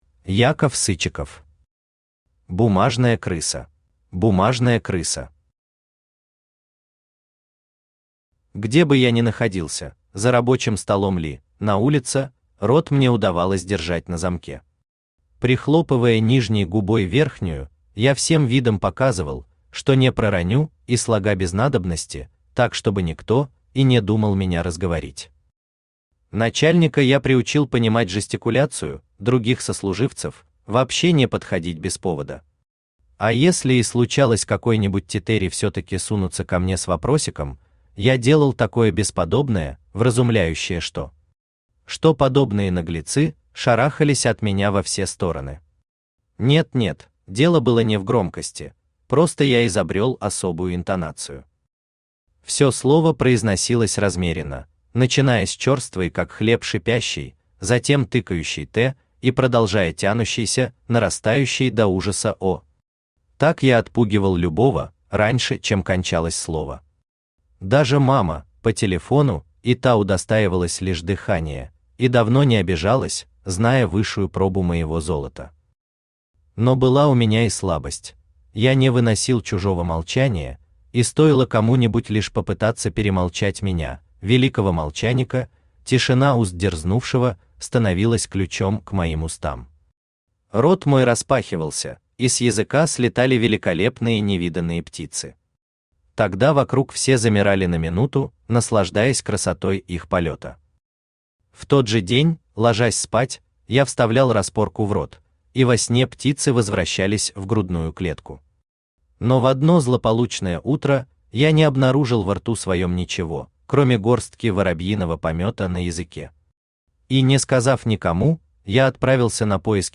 Аудиокнига Бумажная крыса | Библиотека аудиокниг
Aудиокнига Бумажная крыса Автор Яков Сычиков Читает аудиокнигу Авточтец ЛитРес.